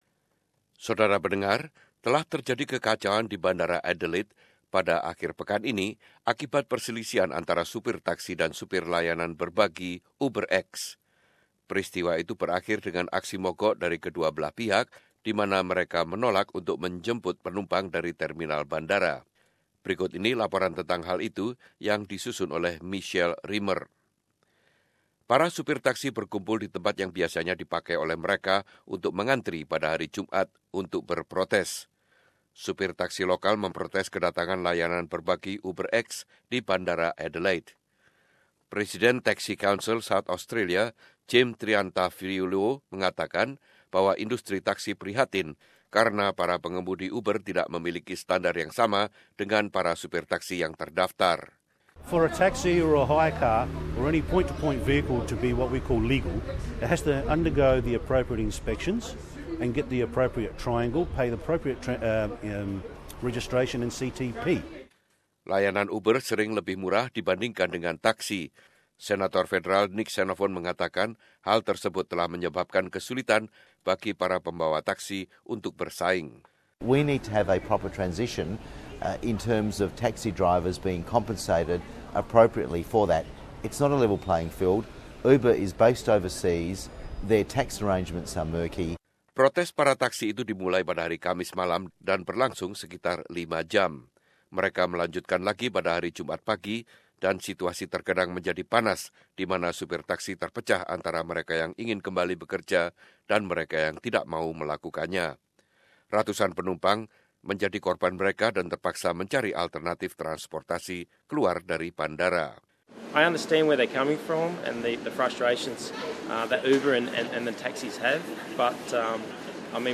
There have been chaotic scenes at Adelaide airport as a dispute between taxi drivers and ride sharing service UberX (oo-ber ex)boiled over.